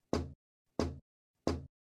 BIRDSTUK.ogg